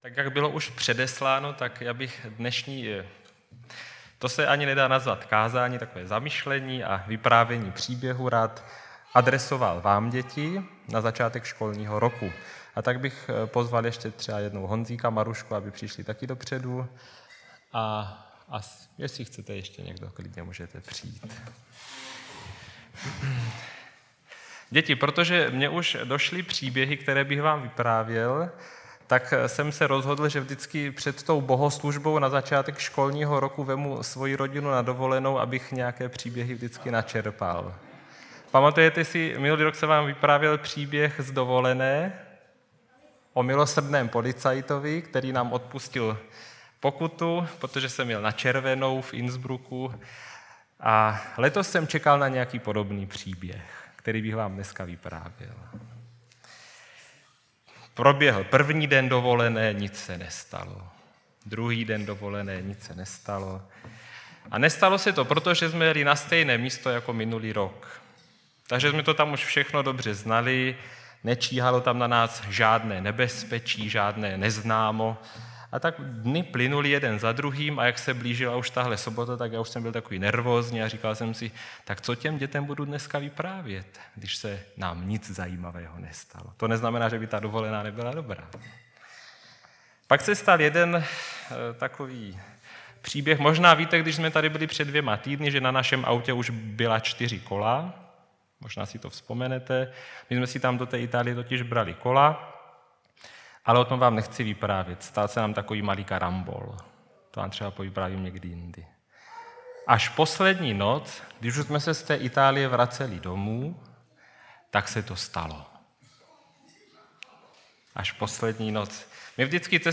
O půlnoci v kleci – bohoslužba na začátek školního roku